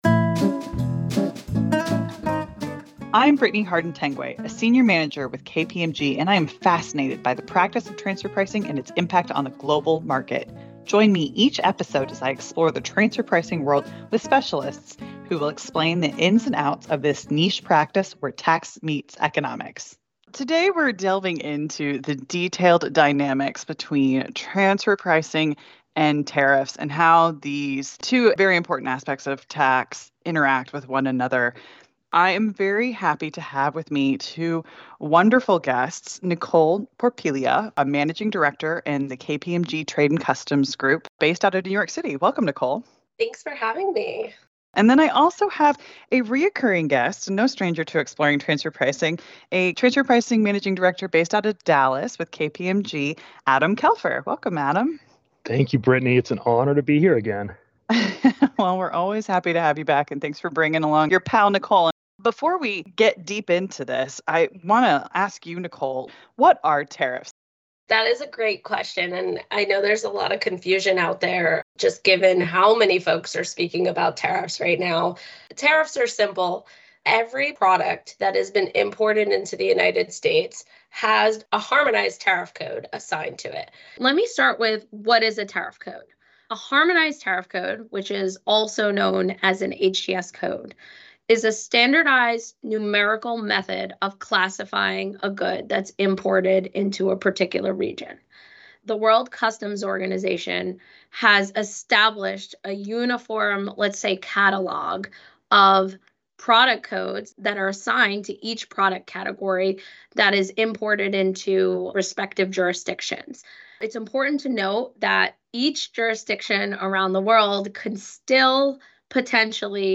Tax Podcast